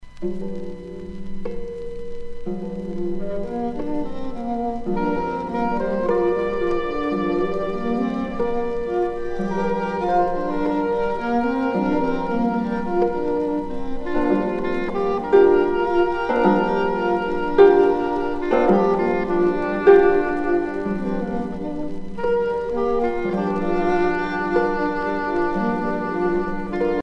harpe celtique
Pièce musicale éditée